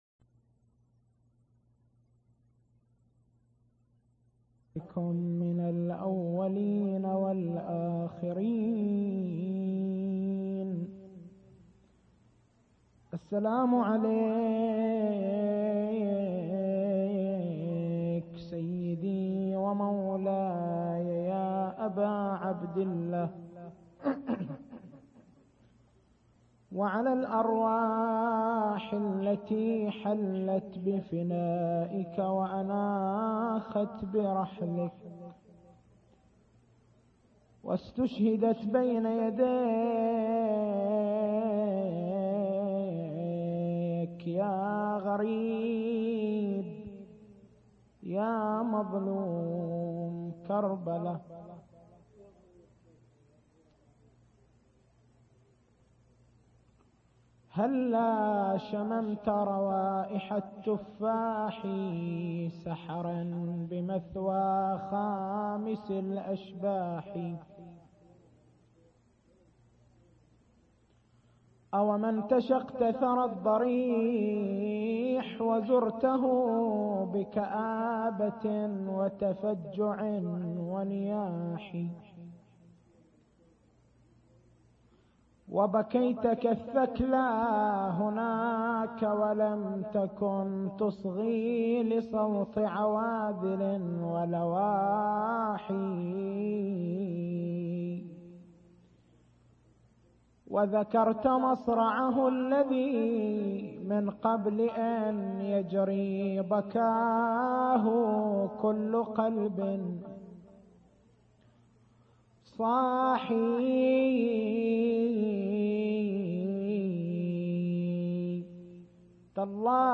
محاضرات نبوية